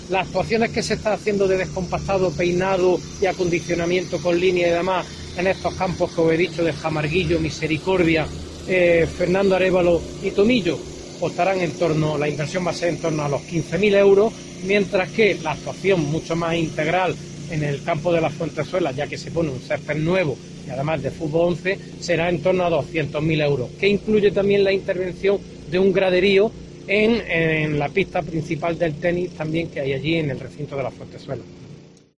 José maría Álvarez, concejal de deportes